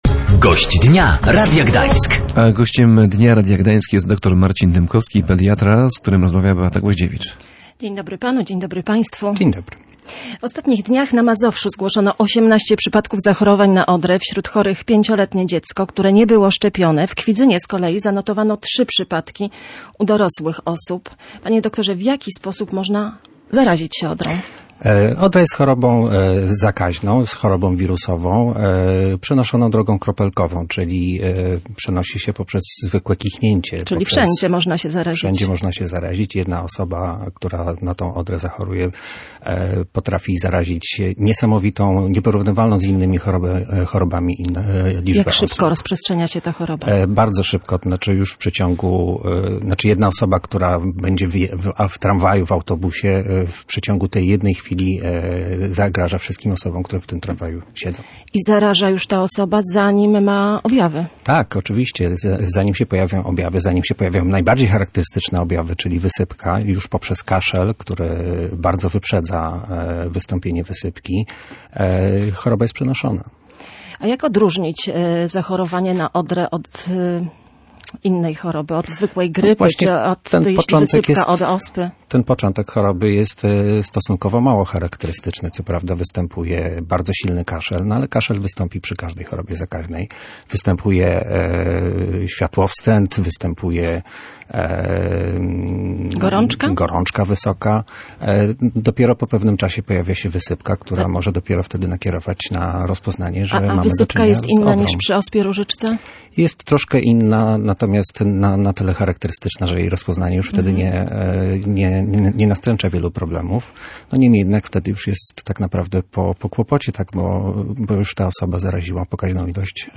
pediatra.